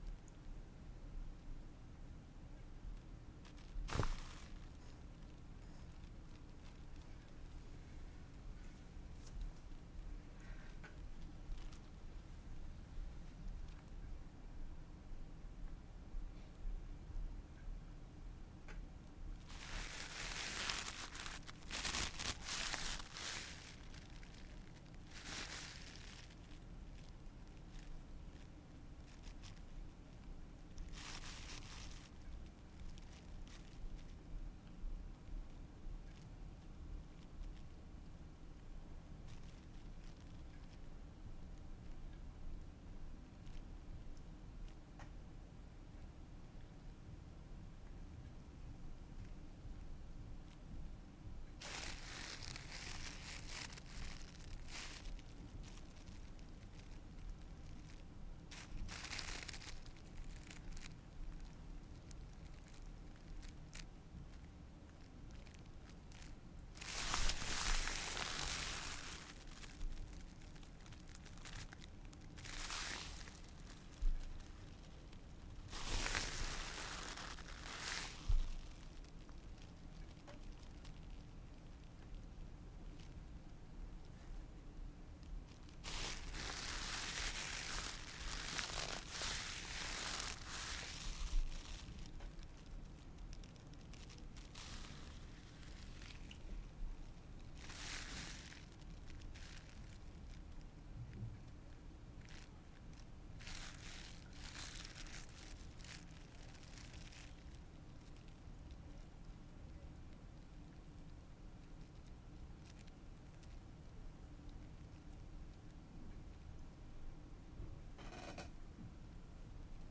咔嚓声和嗡嗡声 " 冲床塑料触点1
描述：打孔位于64盎司塑料容器上。 除非另有说明，否则在Behringer Eurorack UB802上使用舒尔PG57记录。一些声音用接触式麦克风或智能手机录制。
标签： 塑料 弹响 冲头 工具
声道立体声